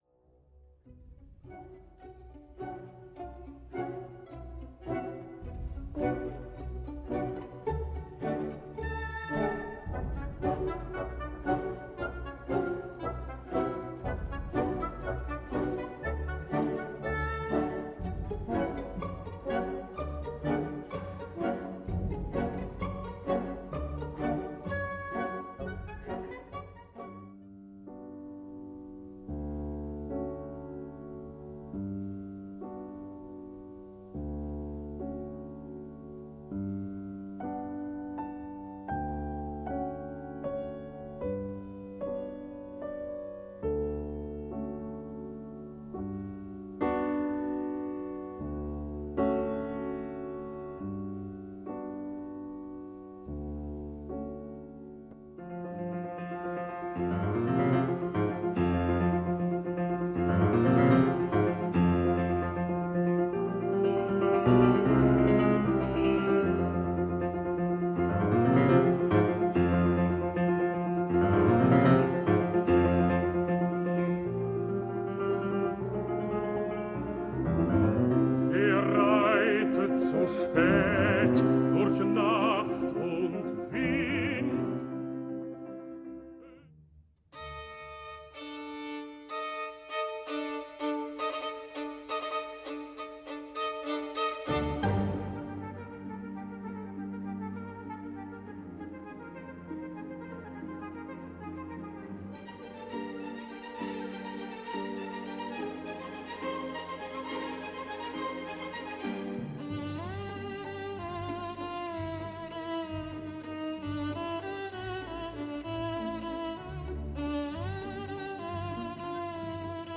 – Dies Irae (chant Grégorien)